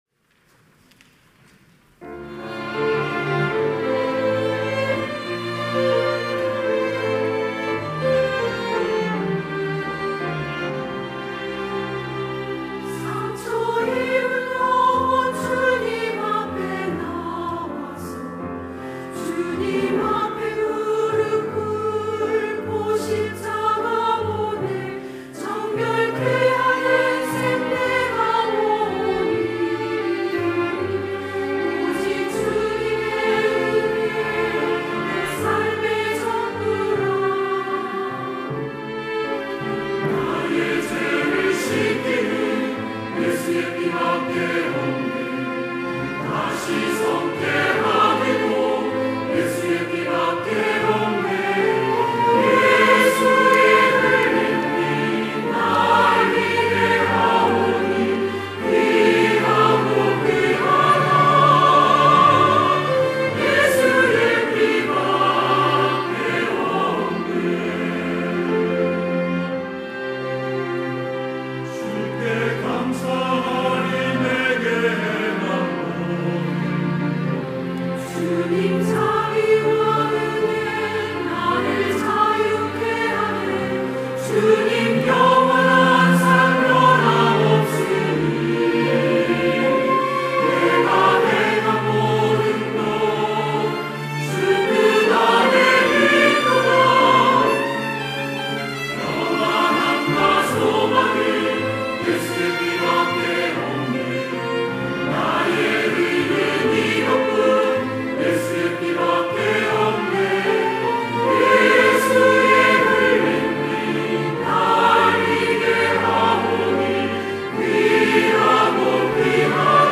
할렐루야(주일2부) - 나의 죄를 씻기는
찬양대